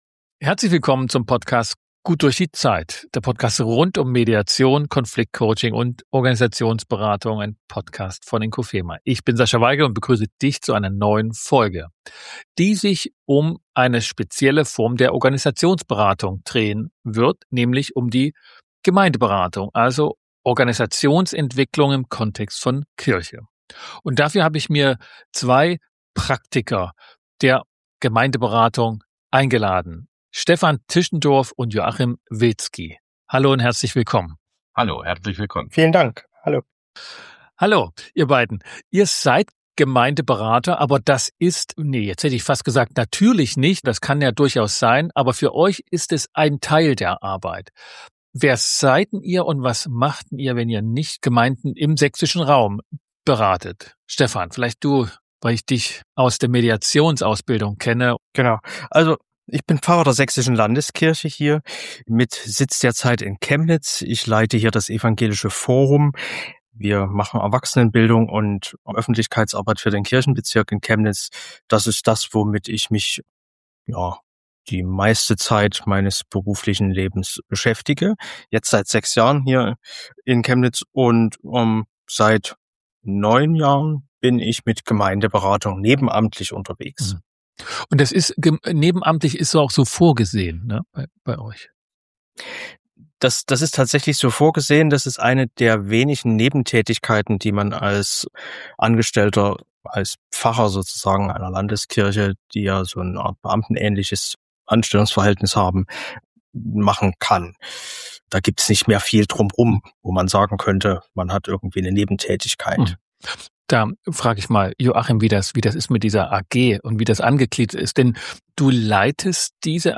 #266 GddZ - Gemeindeberatung. Im Gespräch